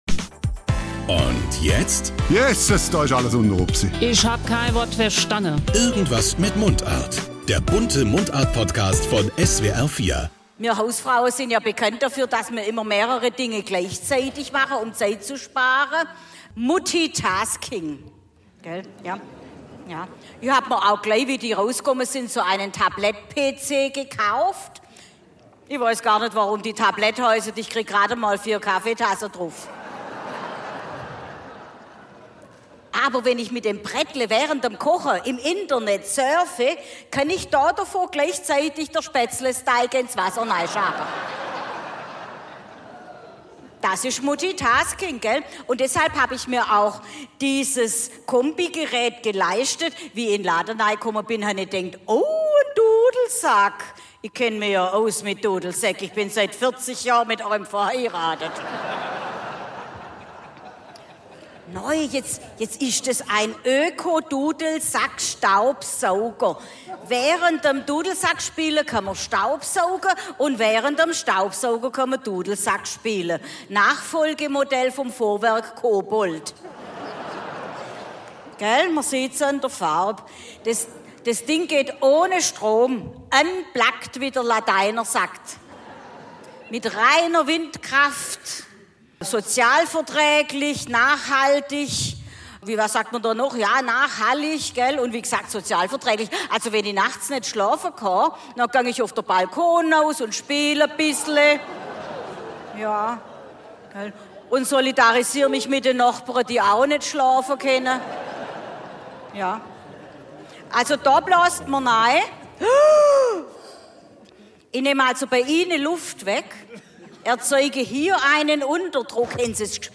Mundart und Musik, Künstler aus dem Land zum Nachhören
Sonntagabend ist in SWR4 Baden-Württemberg Dialekt-Kultur im Programm.